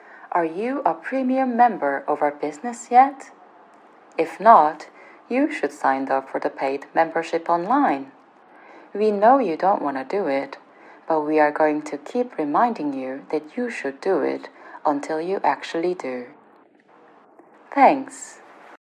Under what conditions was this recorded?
Operator_filtered3.mp3